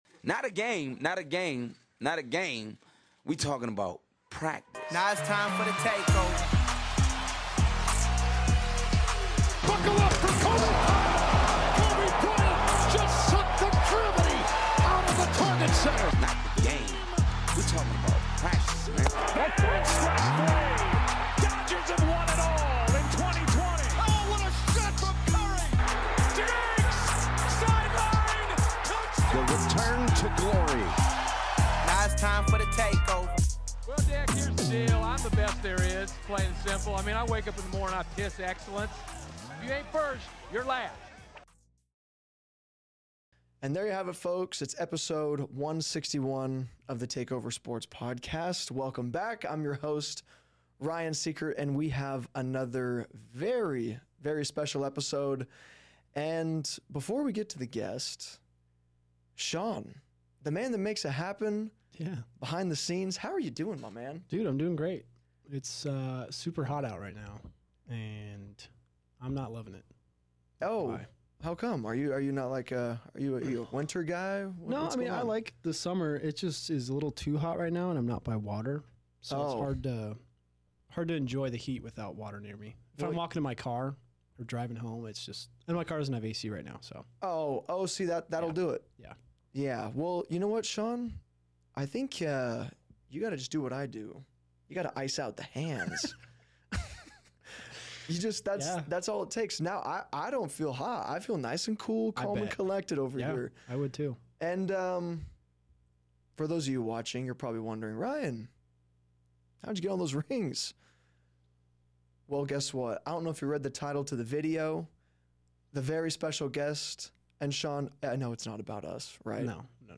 Whether you're a sports enthusiast, a gamer, or someone curious about the evolving world of eSports, this episode is packed with valuable information and engaging conversation. 00:00 Introduction and Opening Remarks 00:44